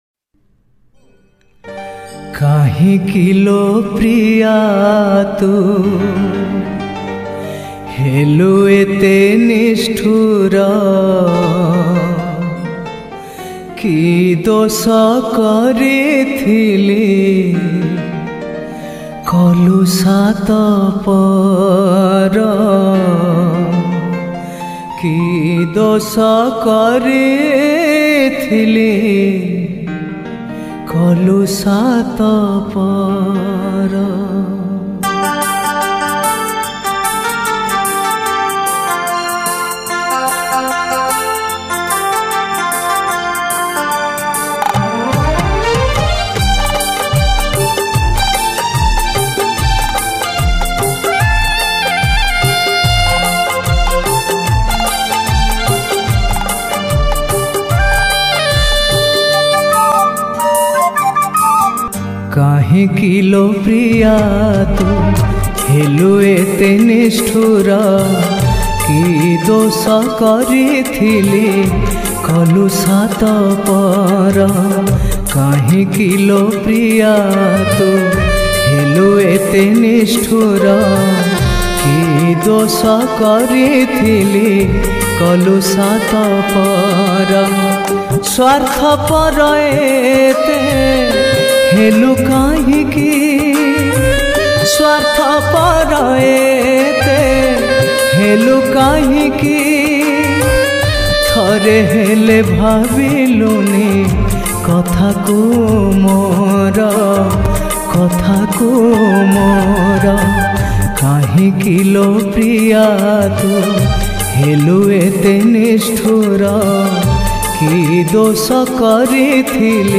Odia New Sad Song
New Odia Album Songs